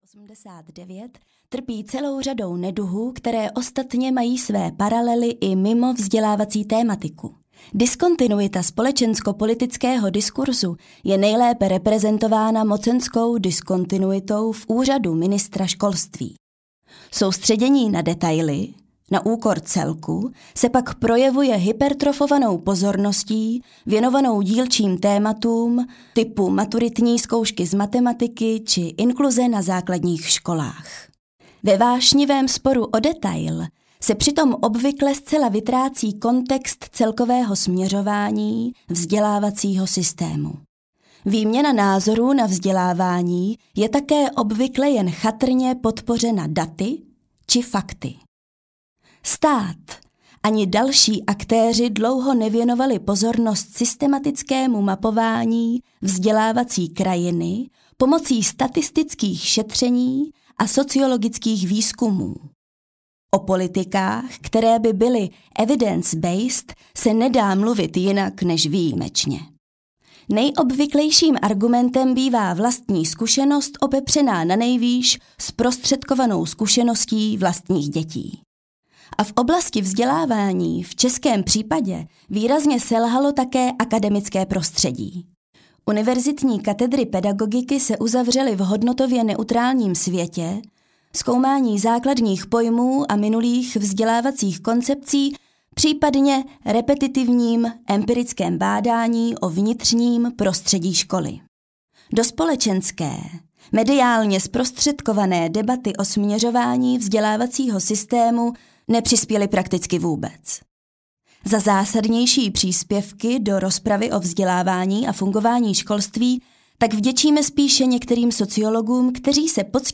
No Future audiokniha
Ukázka z knihy